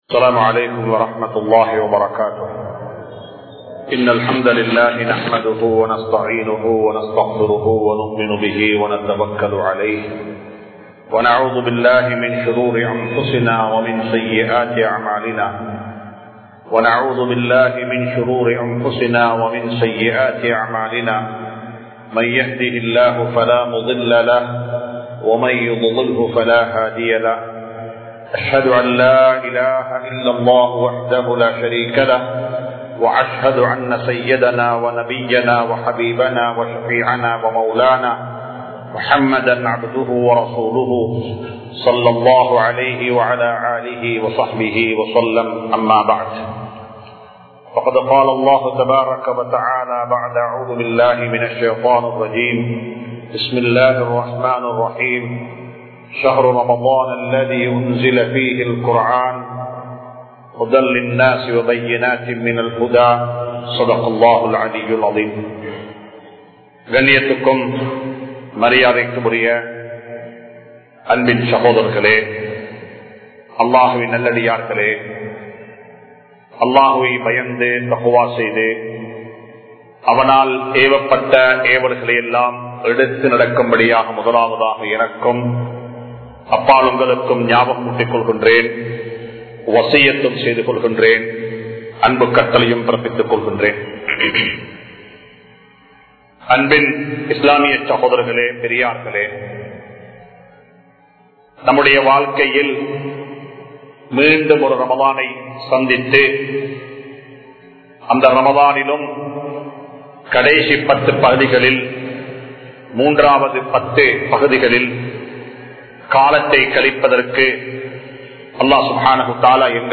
Al Quran Koorum Kudumba Vaalkai (அல்குர்ஆன் கூறும் குடும்ப வாழ்க்கை) | Audio Bayans | All Ceylon Muslim Youth Community | Addalaichenai